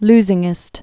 (lzĭng-ĭst)